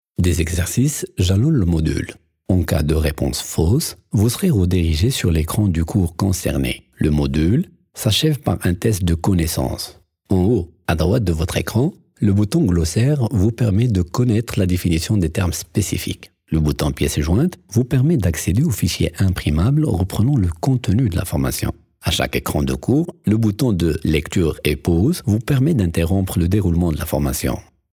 Voix off
Je peux interpréter des textes en qualité de comédien voix off avec un timbre de voix variant et s'adaptant parfaitement aux contextes et aux personnages. Voici une de mes demo voix off annonce radio